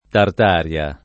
tart#rLa] top. f. — anche Tataria [tatar&a o tat#rLa], variante meno com. per l’antica regione occupata dai tartari in Asia e in Europa, meno rara però come altro nome dell’od. Repubblica dei Tatari (Russia europea), detta pure Tataristan